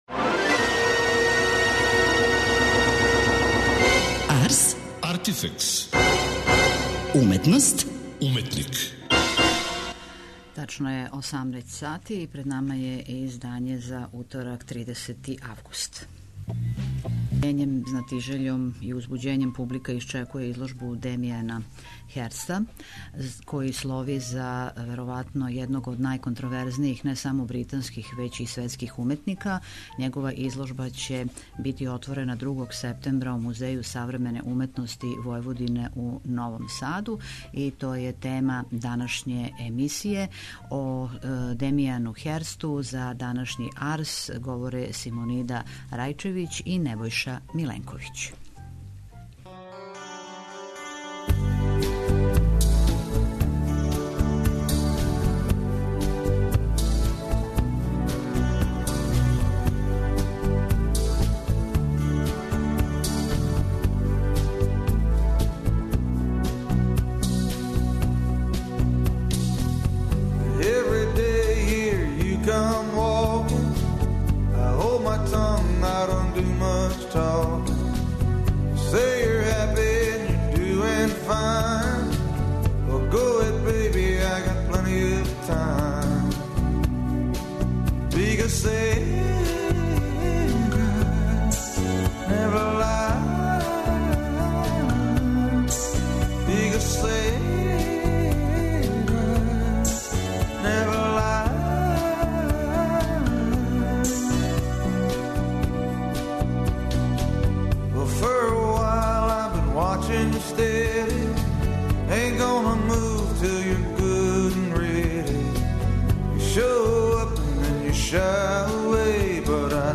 У емисији доносимо занимљив разговор о Херстовом стваралаштву